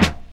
Snare (70).wav